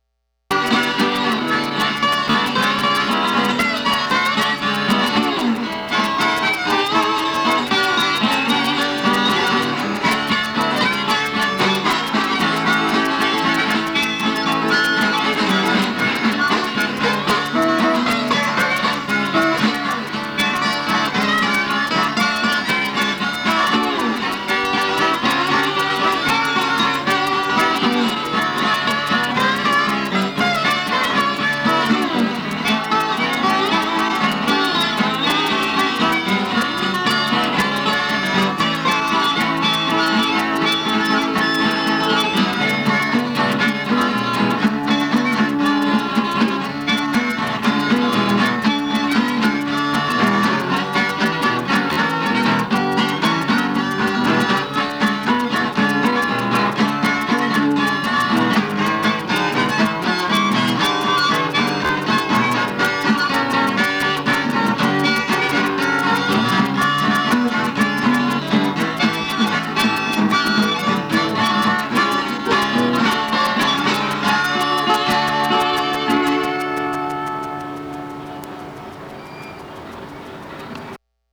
It was down on Edith Street, south of Huning-Huning in the heart of ‘Burque, where the paths of an African-American bluesman from the South, a white folkie from the Windy City and an indigenous rocker from Washington state’s Quinault Nation all came together.